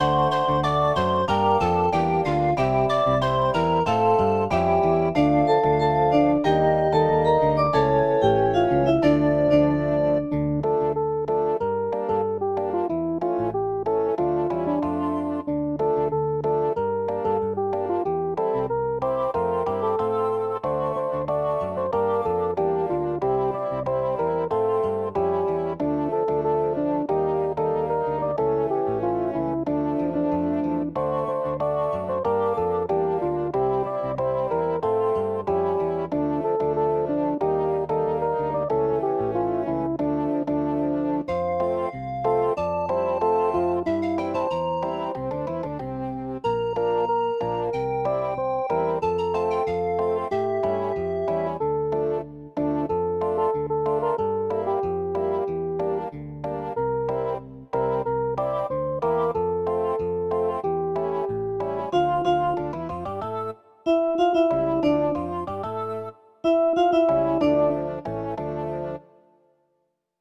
І мелодія відповідна, бадьора! 12 friends
На жаль в мене не читається приспів. там де прискорення випадають ноти. frown
Неякісний запис.